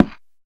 creaking_heart_place3.ogg